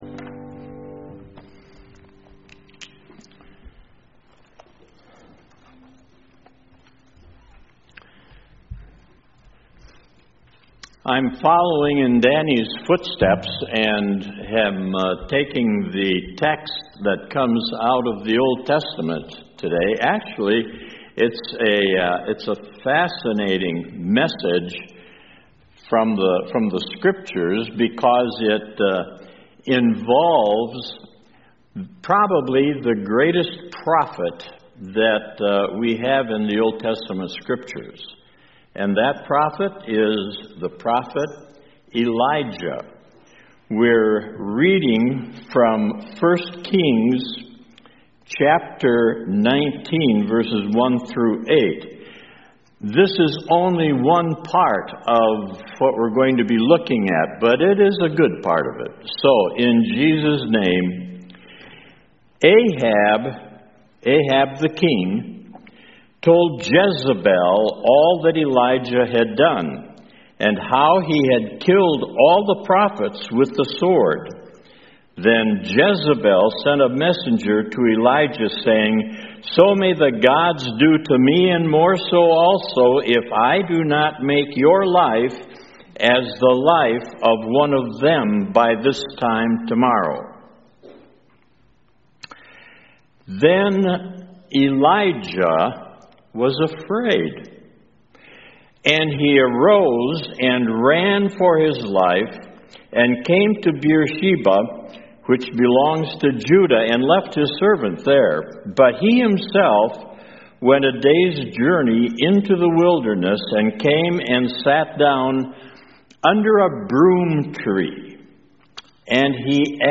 CoJ Sermons